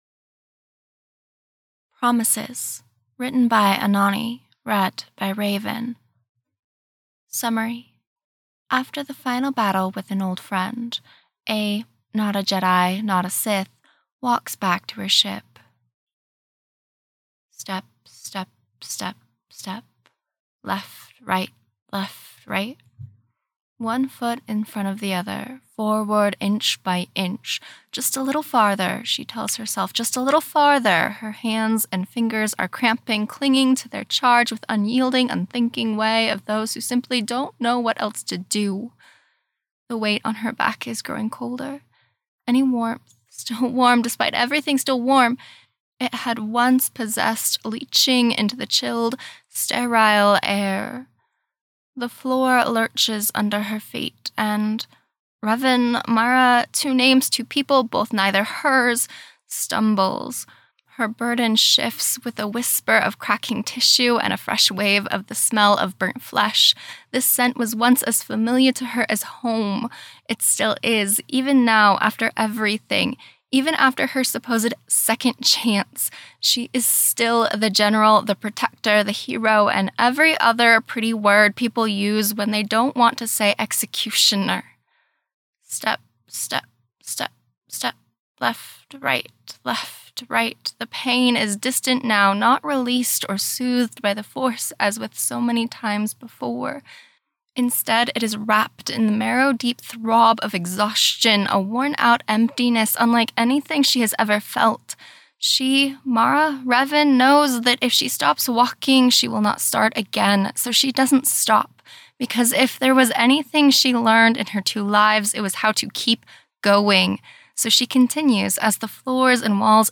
[Podfic] Promises